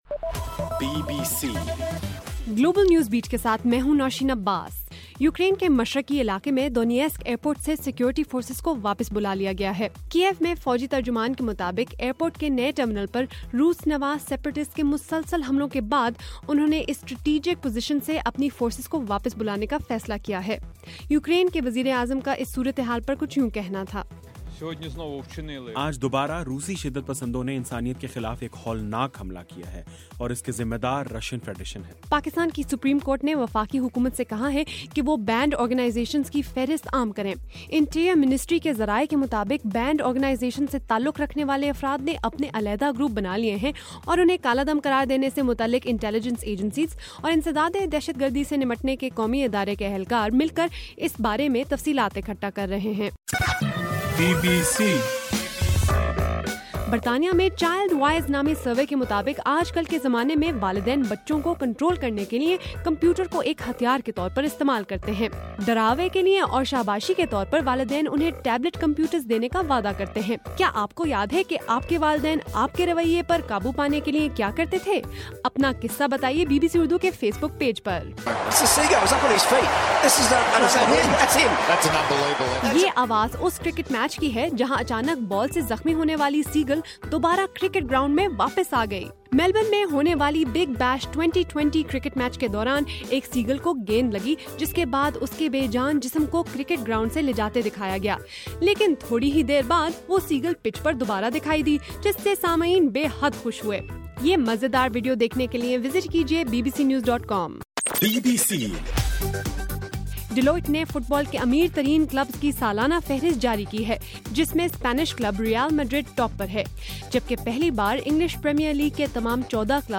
جنوری 22: رات 8 بجے کا گلوبل نیوز بیٹ بُلیٹ